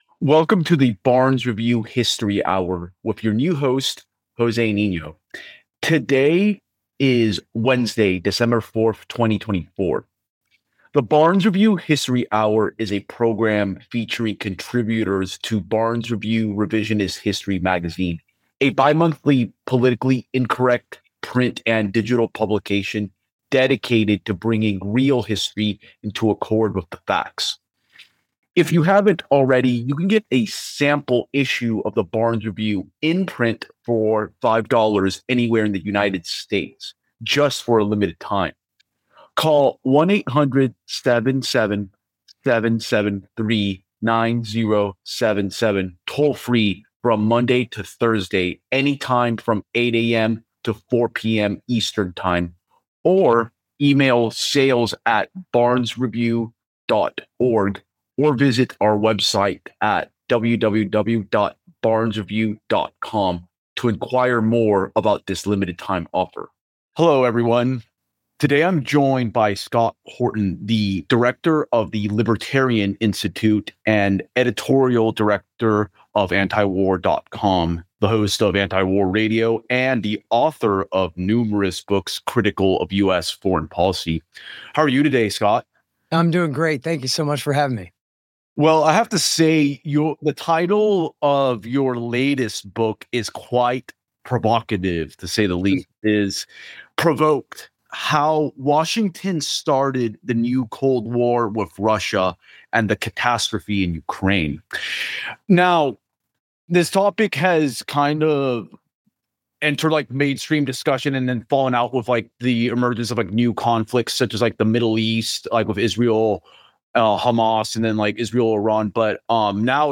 AFP’s interview with author